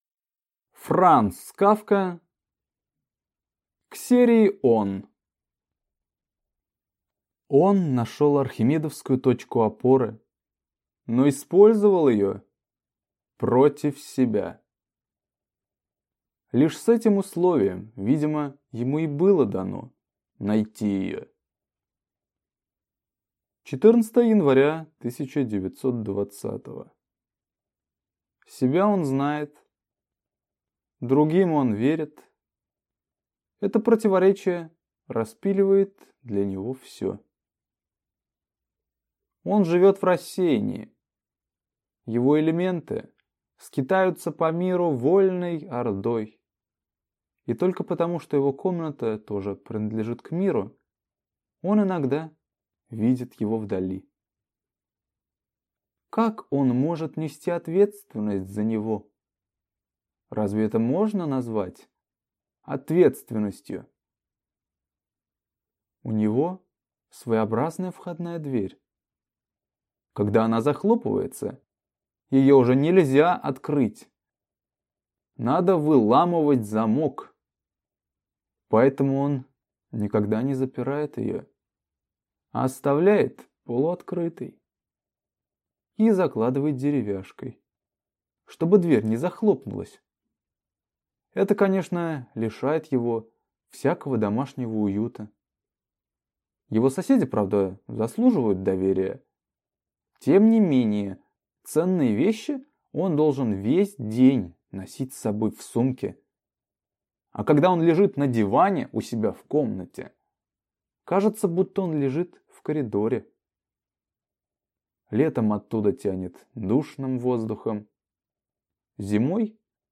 Аудиокнига К серии «Он» | Библиотека аудиокниг